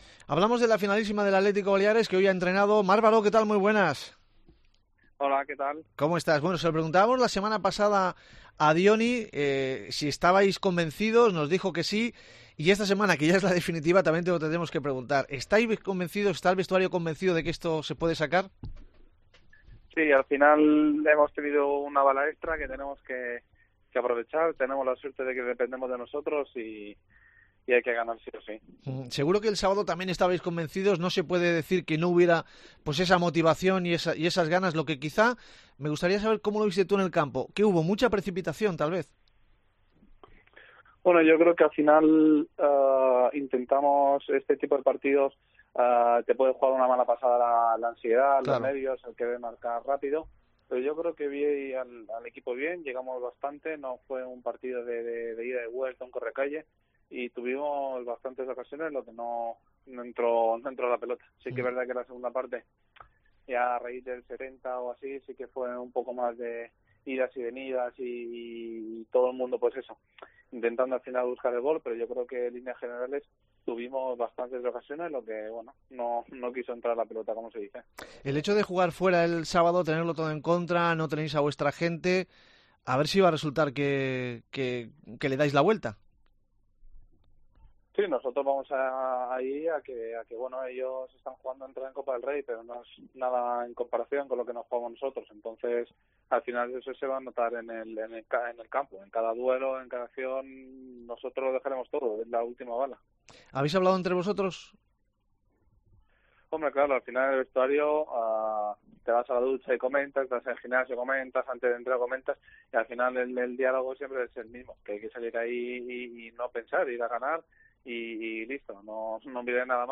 AUDIO: Hablamos con el lateral balearico antes de la final de Las Gaunas. El equipo se juega la permanencia